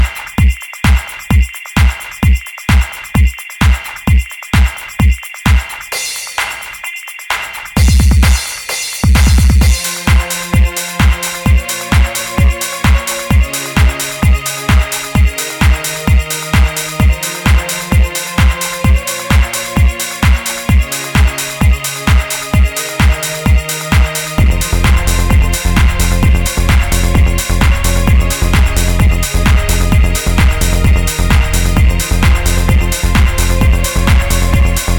Жанр: Русская поп-музыка / Поп